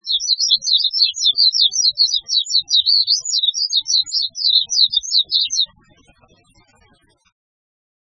〔メジロ〕チィーチィー／低山地以下に棲息，多い・留鳥，11.5p，雌雄同色
mejiro.mp3